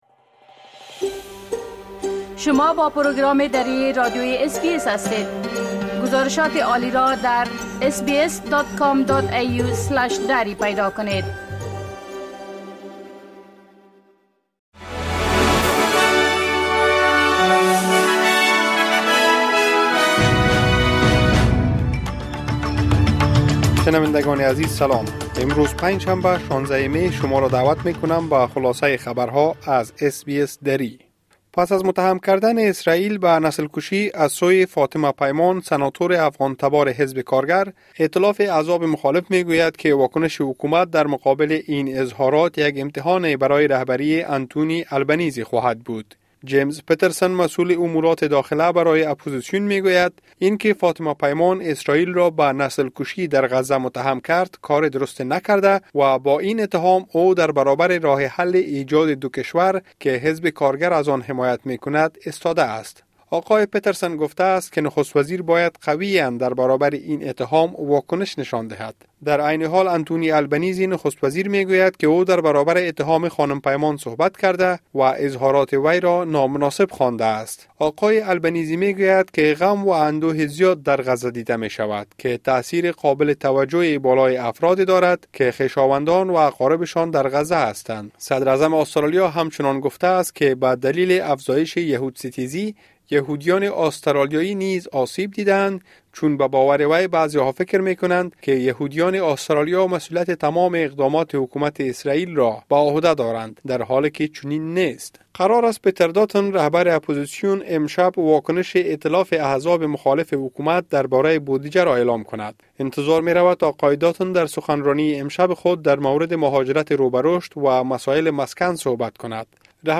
خلاصۀ مهمترين اخبار روز از بخش درى راديوى اس بى اس|۱۶ می ۲۰۲۴